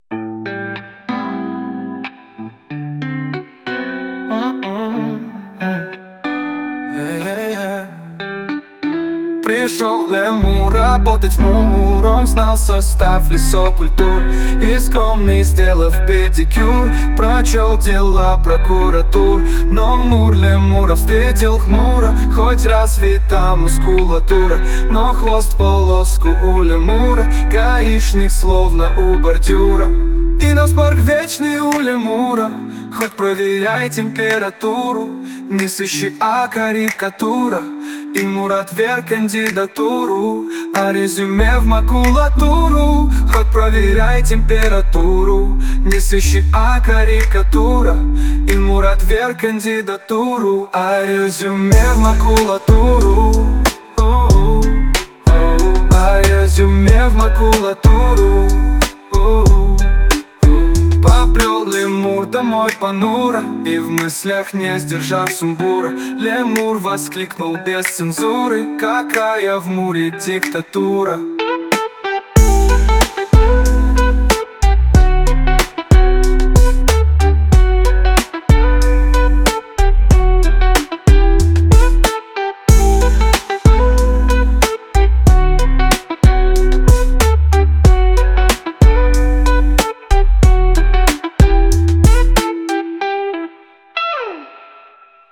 Пришёл лемур работать в МУР. Ар-эн-би и соул 2в 4дб